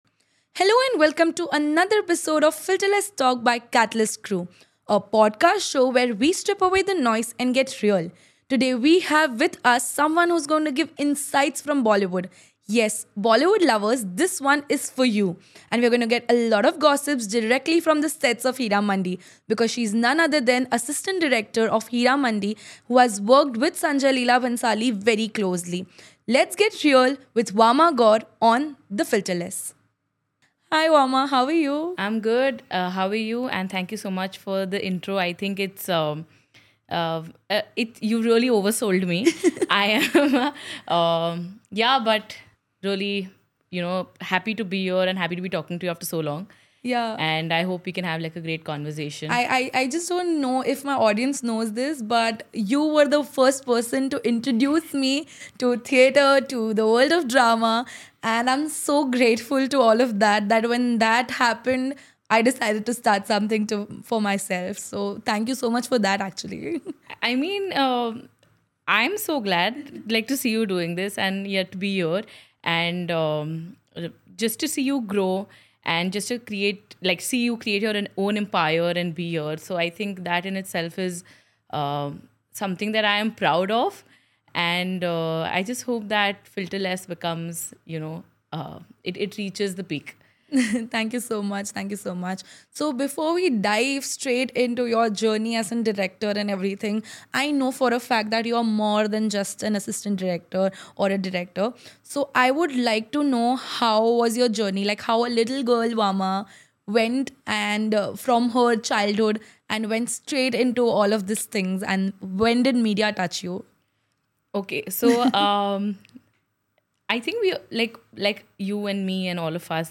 In this episode of Filterless Talk, we strip away the noise and sit down with three extraordinary women who’ve chosen to rise — on their own terms.